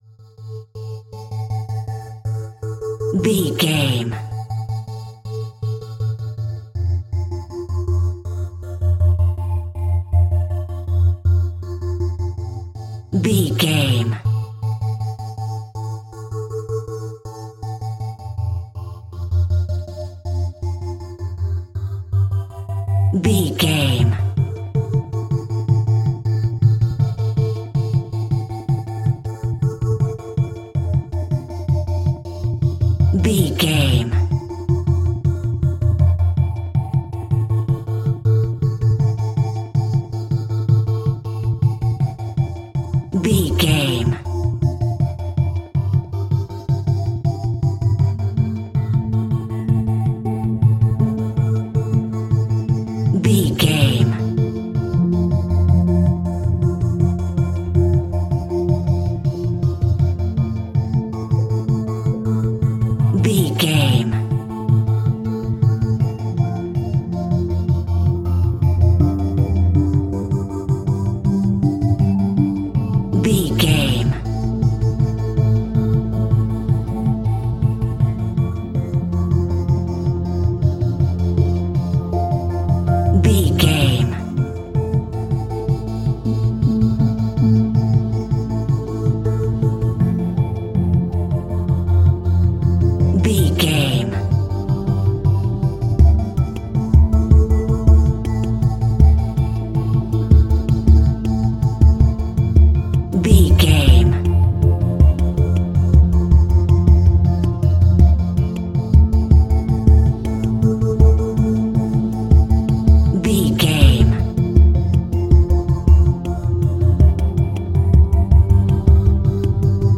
Aeolian/Minor
scary
tension
ominous
dark
suspense
haunting
eerie
synthesiser
drums
instrumentals
mysterious